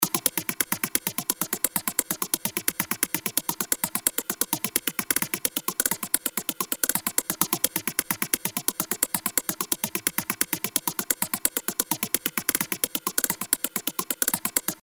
Geiger.wav